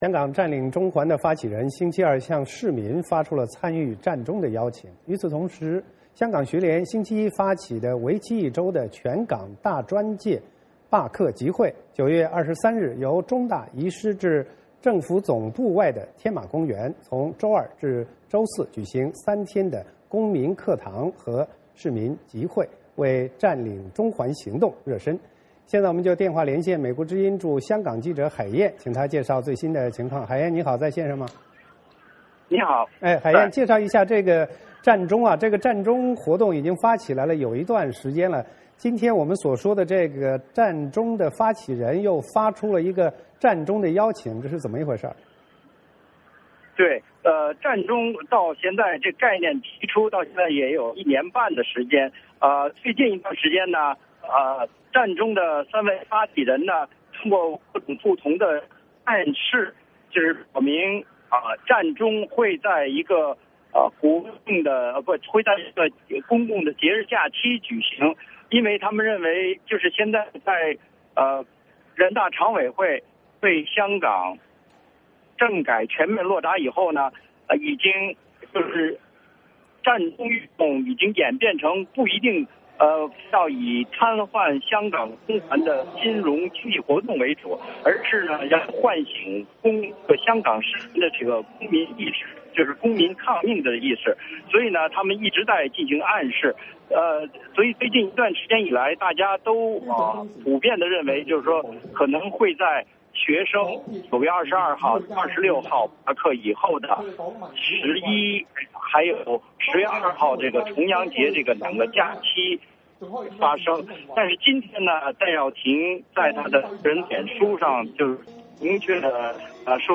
VOA连线：香港占中行动即将开启